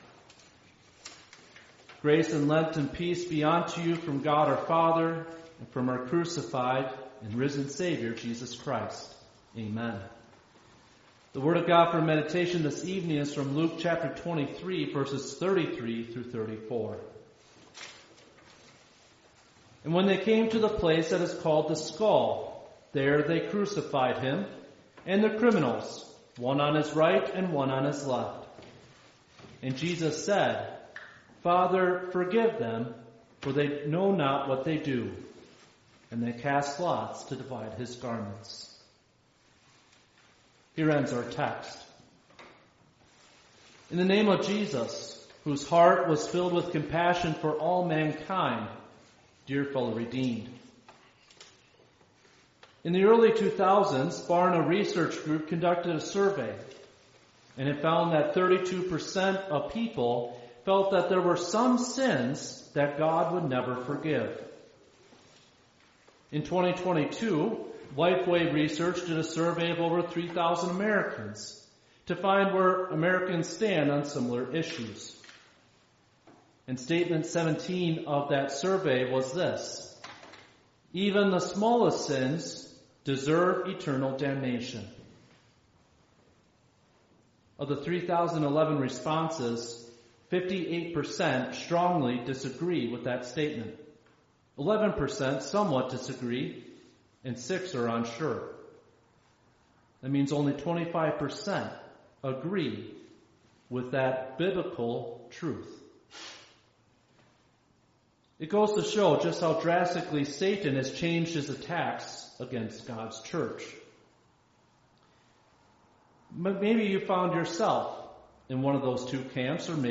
5th-Midweek-Lenten-Service.mp3